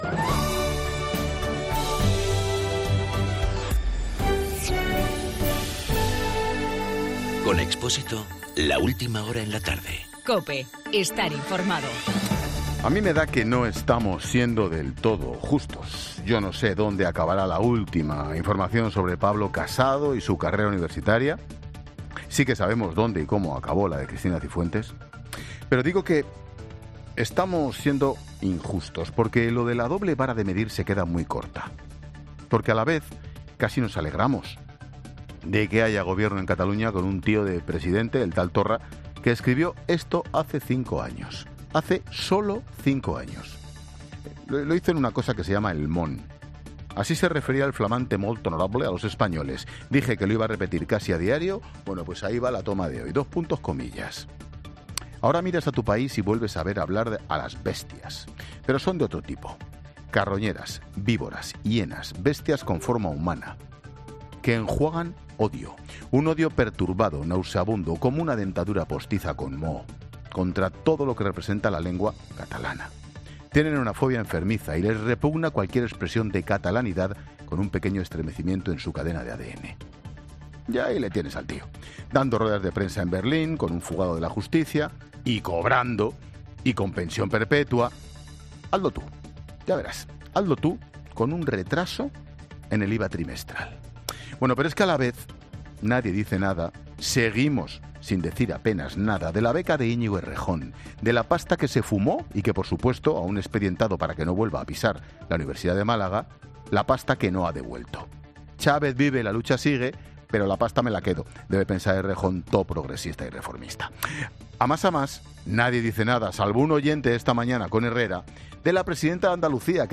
Monólogo de Expósito
El comentario de Ángel Expósito sobre Torra.